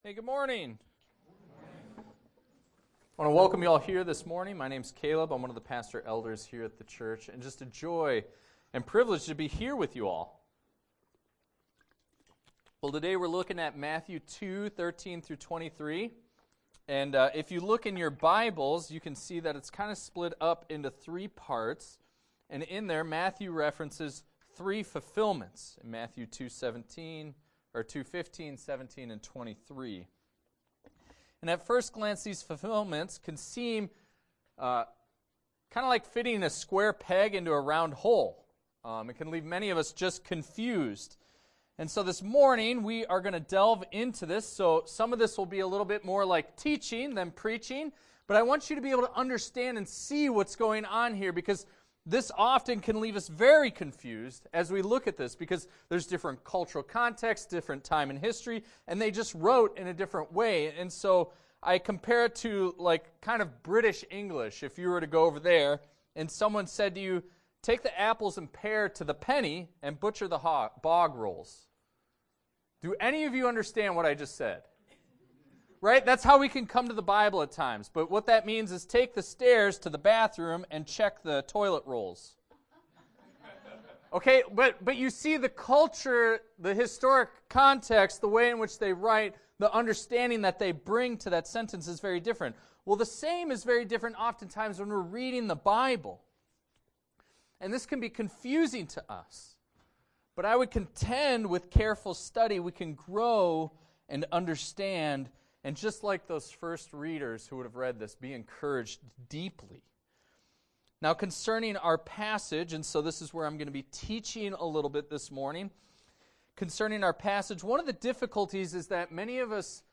Sermon on Jesus being The Ultimate Fulfillment